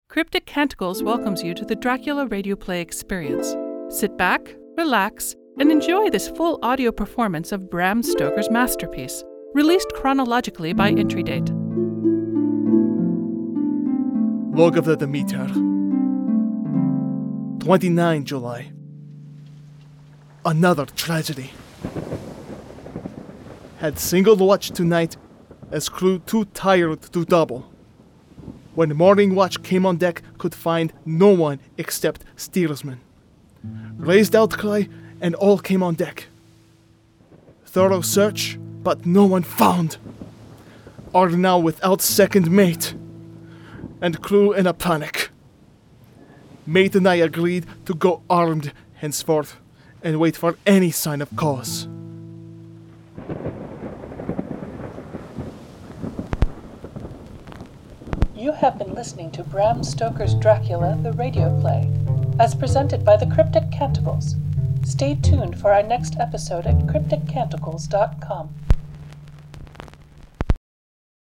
This is a rebroadcast of the original 2017 work.